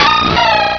pokeemerald / sound / direct_sound_samples / cries / azumarill.aif
-Replaced the Gen. 1 to 3 cries with BW2 rips.
azumarill.aif